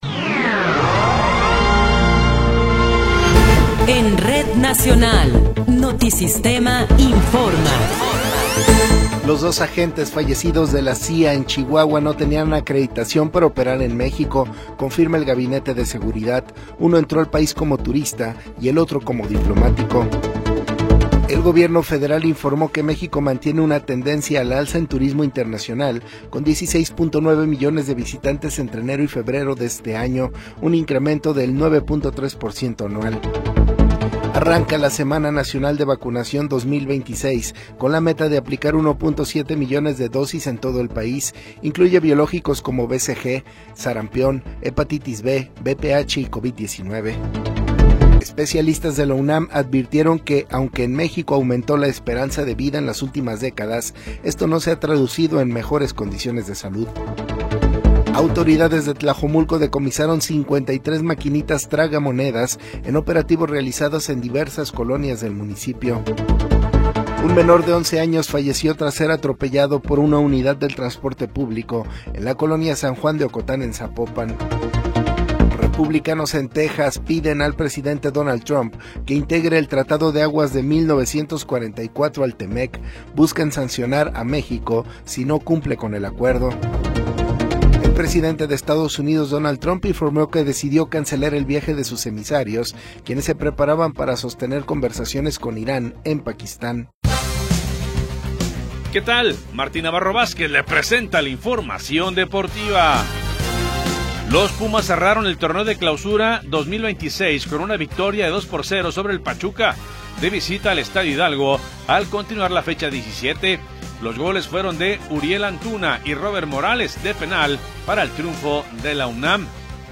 Noticiero 21 hrs. – 25 de Abril de 2026
Resumen informativo Notisistema, la mejor y más completa información cada hora en la hora.